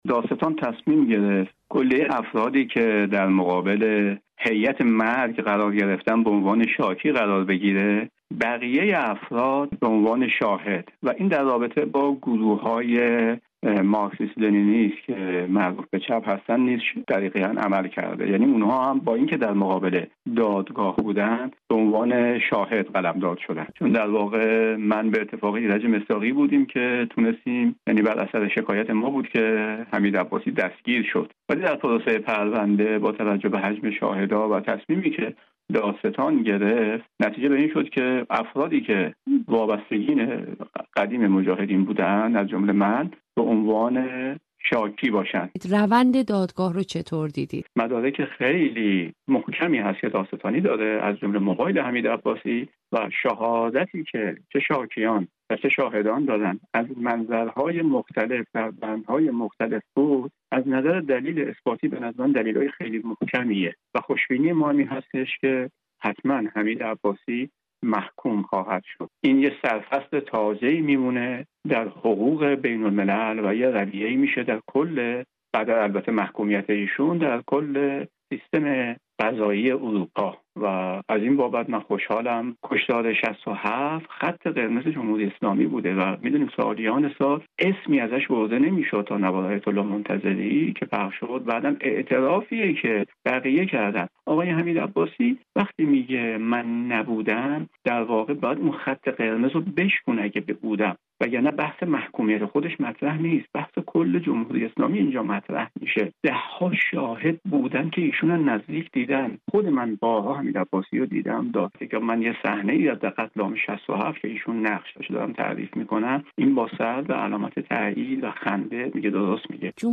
در گفتگو با رادیو فردا